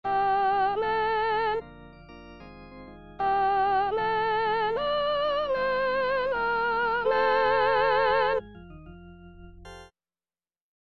Chanté:     S1   S2